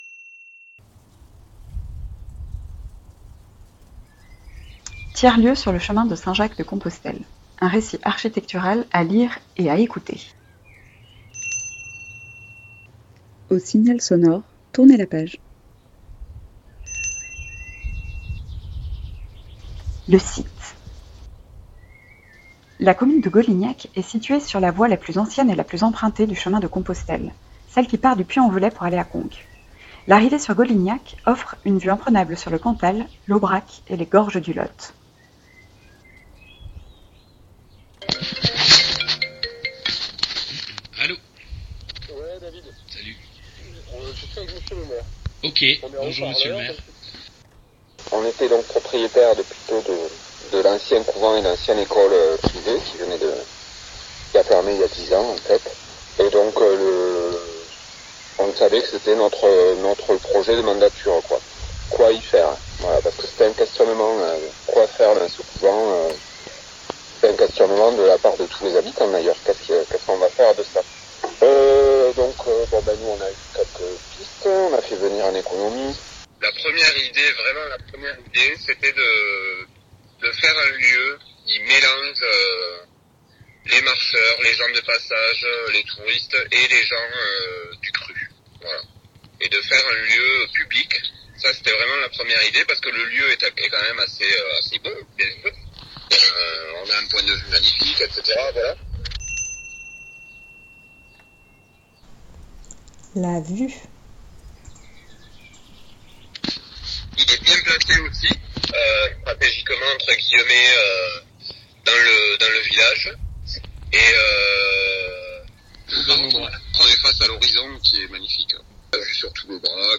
Le récit chaleureux de cette aventure est disponible à l’écoute via ce lien :
Recit-architectural-Golinhac.mp3